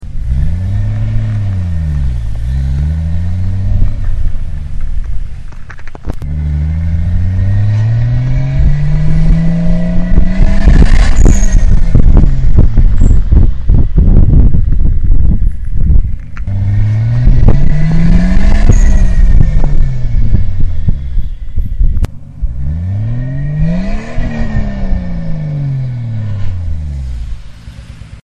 Full 3" Custom Exhaust
All Runs were done @ 10PSI @ 5280ft Altitude.
Sounds were recorded by a microphone, plugged into a laptop in the passenger seat.
#3 My Exhaust Rumble [384kb]